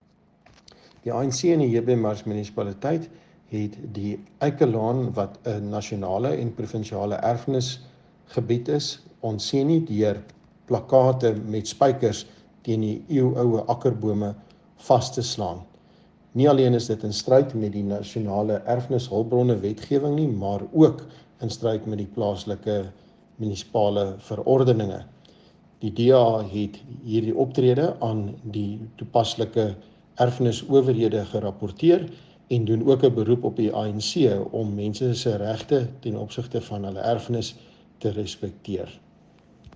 Issued by Cllr Hans-Jurie Moolman – DA Councillor: JB Marks Local Municipality
Note to Broadcasters: Please find linked soundbites in